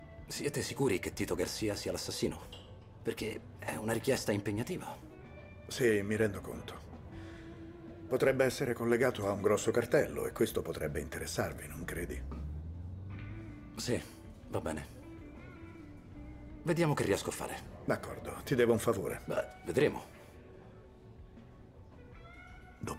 nel telefilm "Goliath", in cui doppia James Wolk.